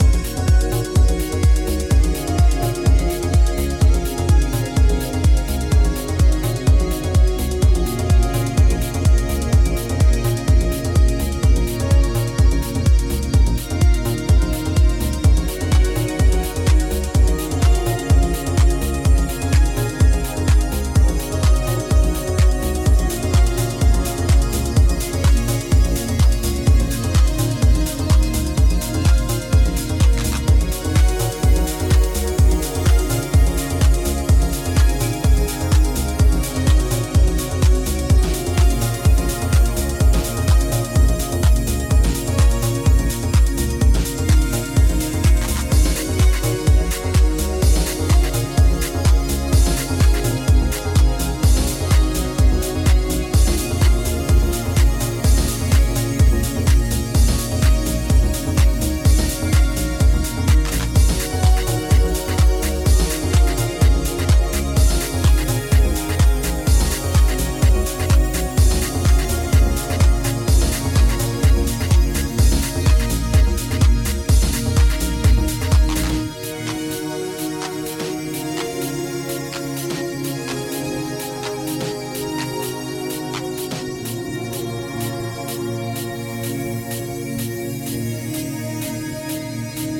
ジャンル(スタイル) TECHNO